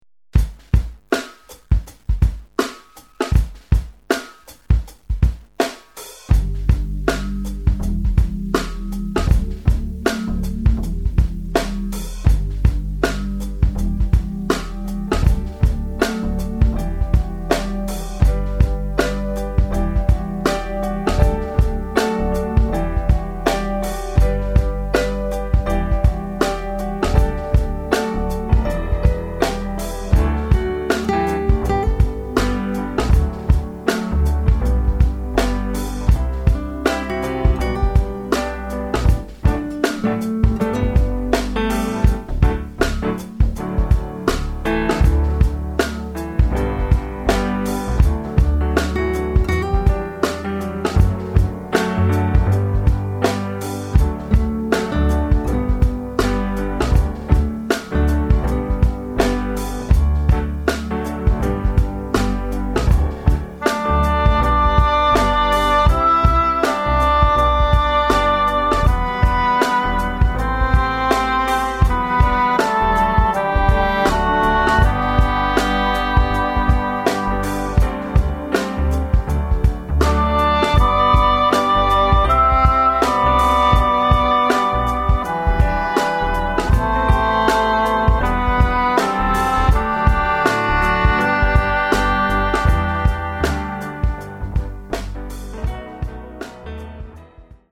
(moderne Adventsmusik)